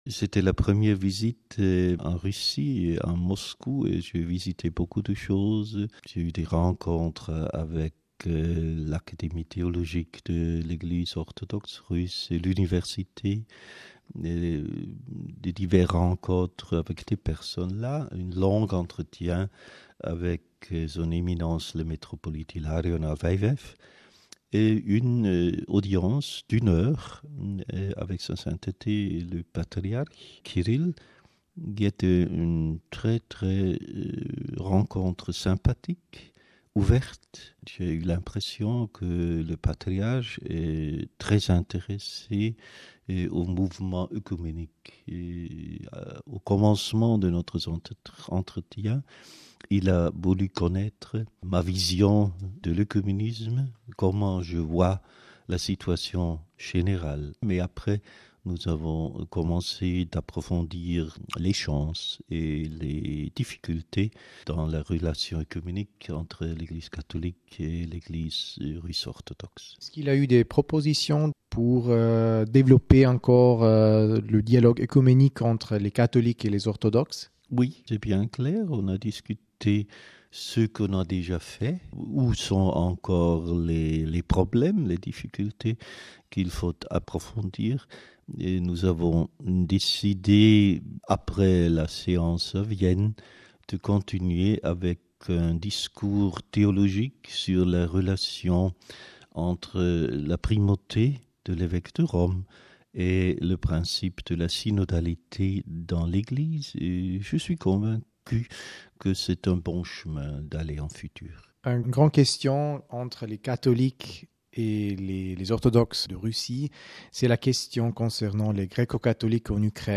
Notre confrère de la rédaction allemande lui a posé quelques questions en français.
Entretien RealAudio